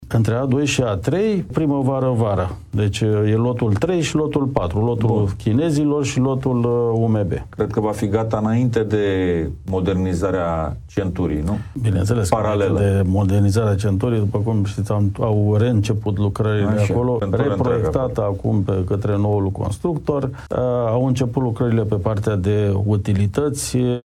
Secretarul de stat Irinel Ionel Scrioşteanu a explicat ritmul în care ar urma să fie încheiate lucrările la autostrada A0, autostrada de centură a Capitalei